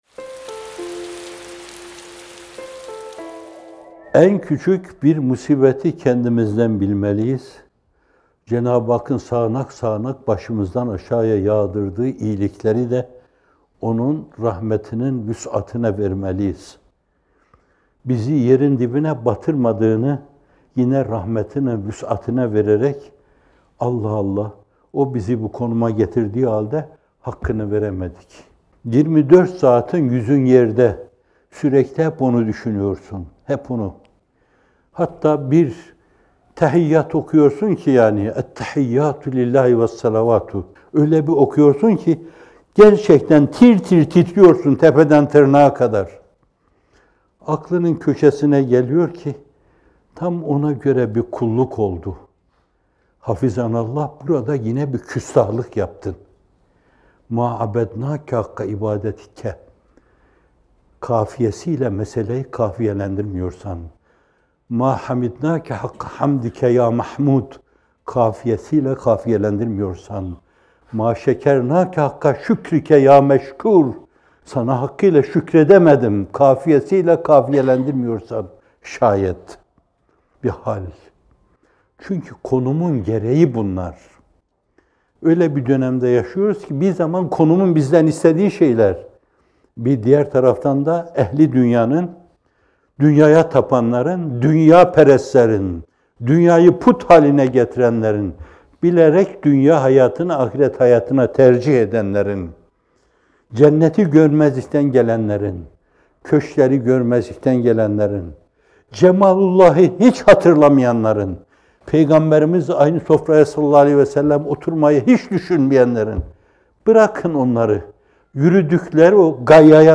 İkindi Yağmurları – Gönül ve Hal İttifakı - Fethullah Gülen Hocaefendi'nin Sohbetleri